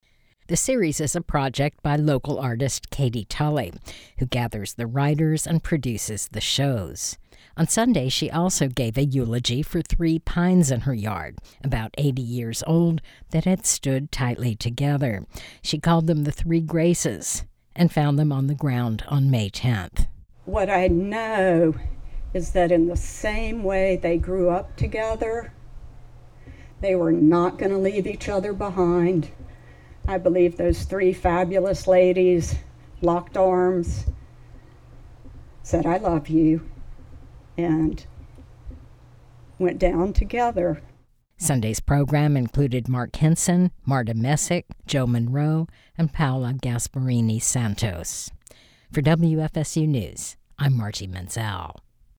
Local writers and poets are reading their work among the shrubs and flowers at Tallahassee Nurseries.